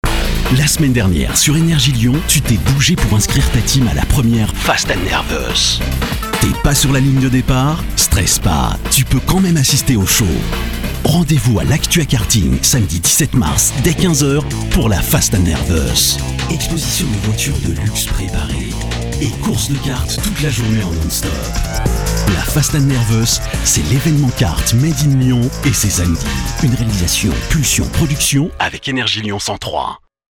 • Spot publicitaire diffuser sur les ondes d'NRJ : >> Ecouter le spot!